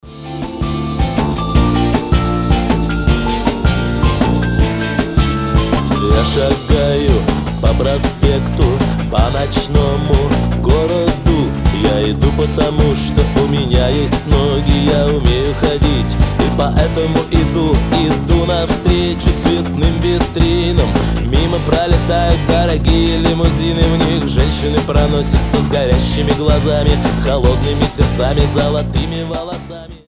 мужской голос
мелодичные
90-е
легкий рок
ностальгия